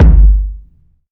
Waka KICK Edited (58).wav